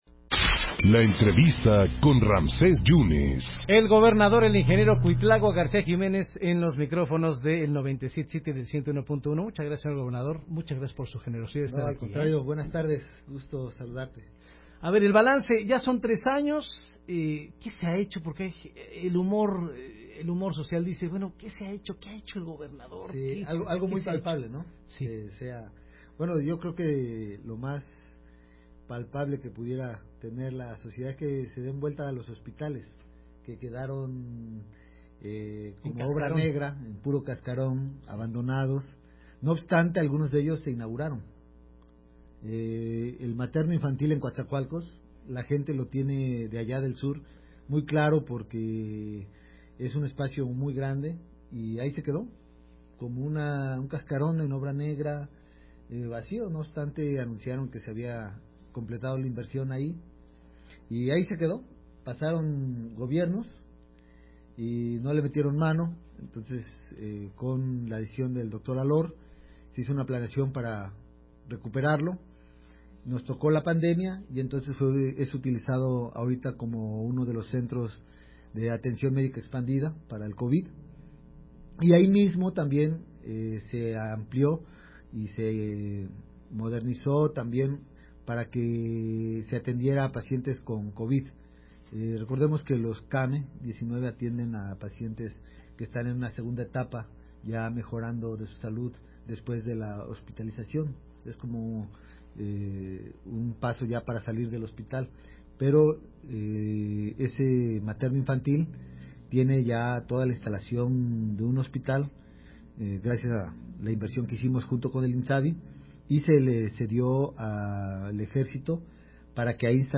Redacción/Xalapa.- El gobernador de Veracruz Cuitláhuac García, platicó para En Contacto sobre las acusaciones que dió un senador a su gobierno sobre ser supuestamente omiso al caso de unos jóvenes levantados.